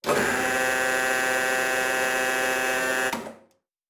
pgs/Assets/Audio/Sci-Fi Sounds/Mechanical/Servo Big 8_2.wav at 7452e70b8c5ad2f7daae623e1a952eb18c9caab4
Servo Big 8_2.wav